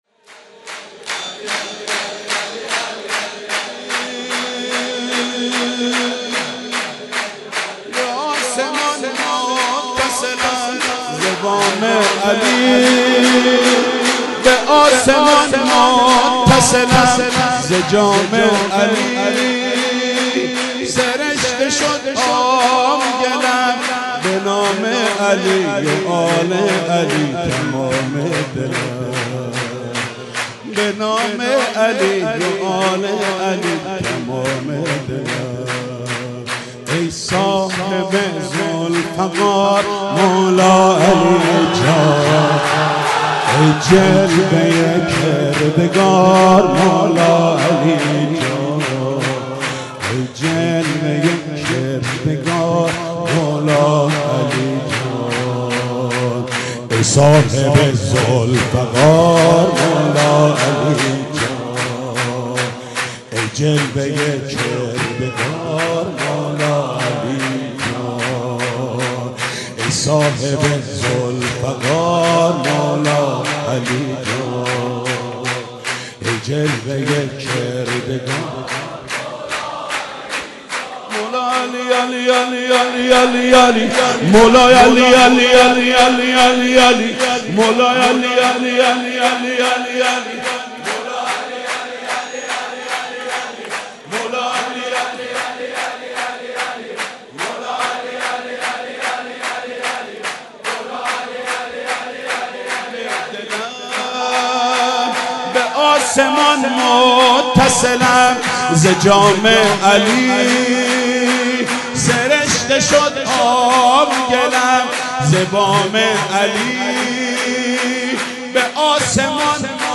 سرود: ای صاحب ذوالفقار مولا علی جان